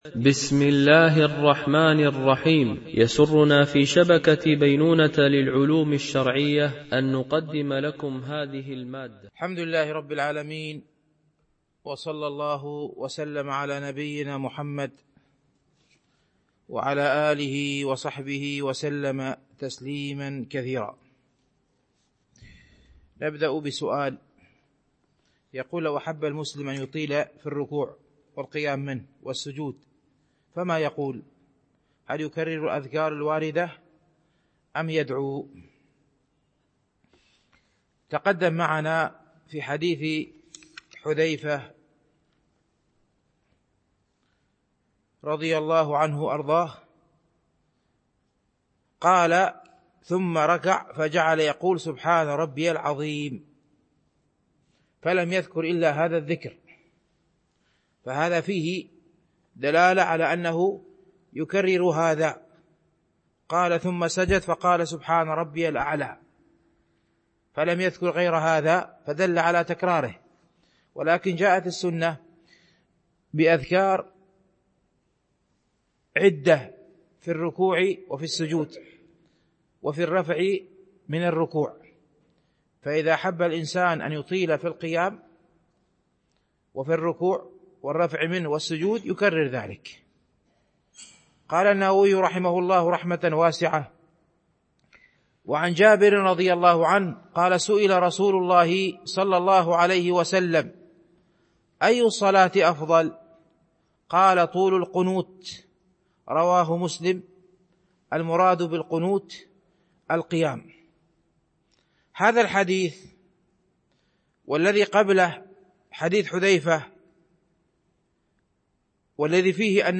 شرح رياض الصالحين – الدرس 306 ( الحديث 1184 - 1191 )